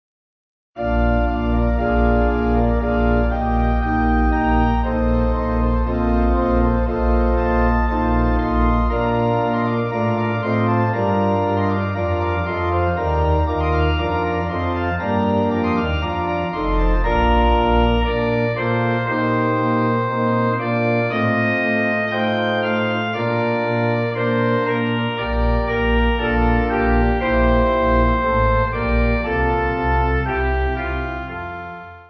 Organ
Easy Listening   Bb